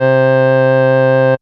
UGLY ORGAN 1.wav